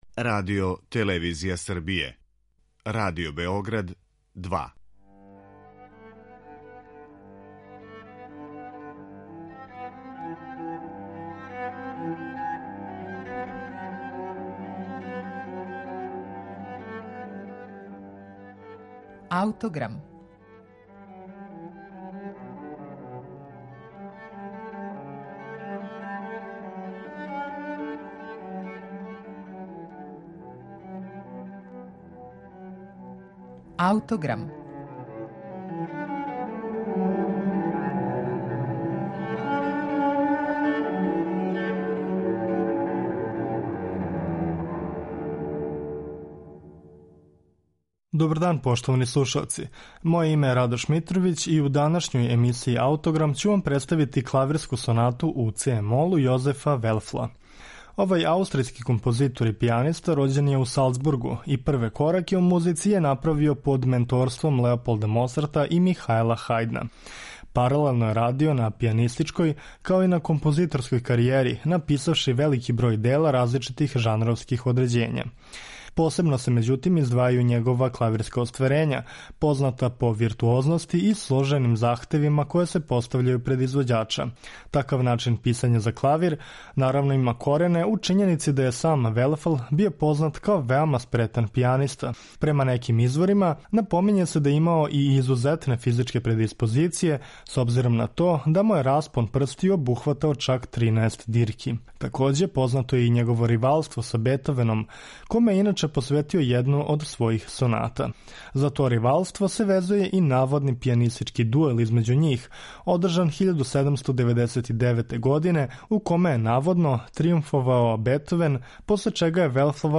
Клавирска соната